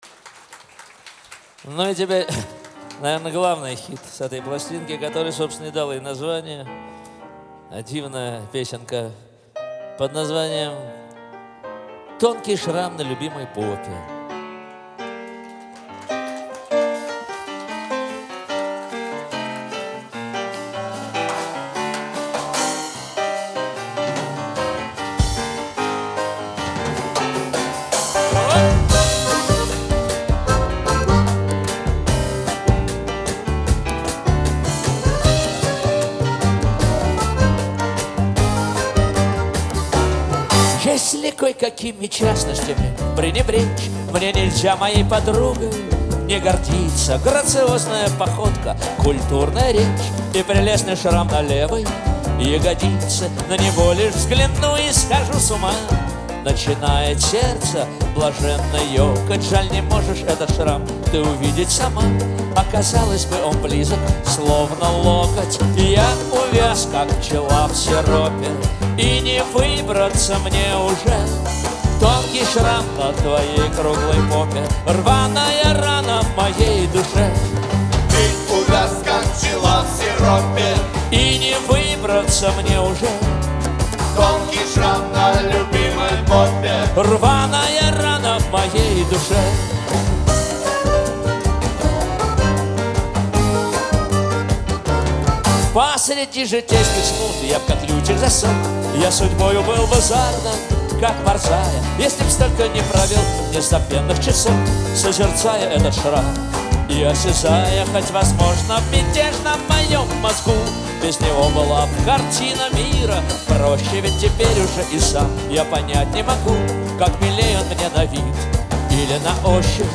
гитара, фортепиано, вокал
скрипка
аккордеон
ударные
контрабас
труба